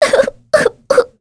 Mirianne-vox-Sad.wav